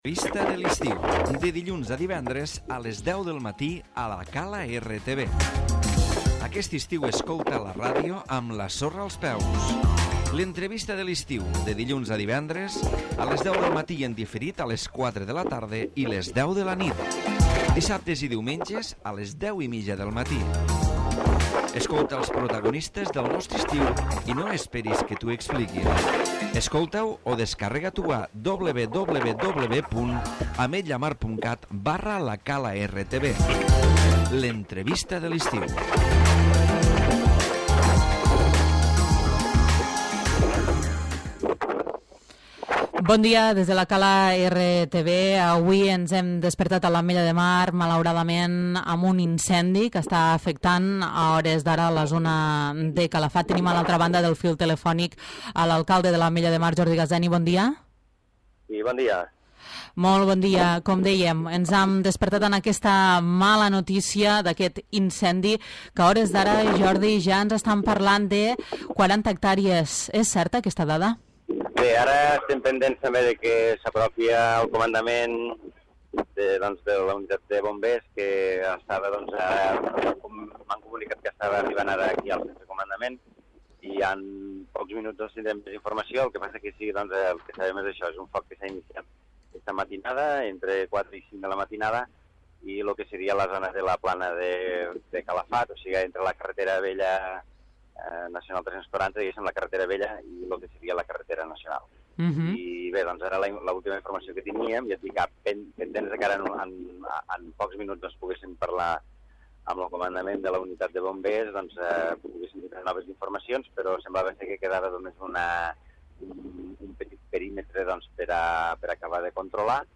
L'Entrevista
Entrevista amb l'alcalde Jordi Gaseni sobre la darrera hora de l'incendi. Ara ens acaben d'informar que hi ha 45 hectàrees cremades i queden 3 petites zones actives.